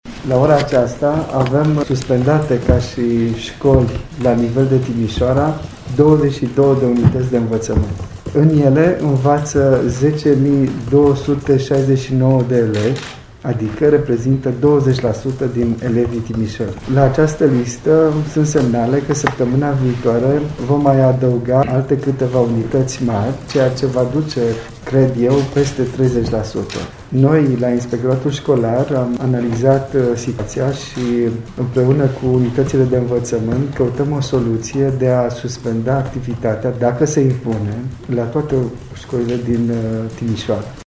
Potrivit inspectorului școlar general adjunct al județului Timiș, Cosmin Hogea, se analizează posibilitatea ca toate școlile din Timișoara să fie închise: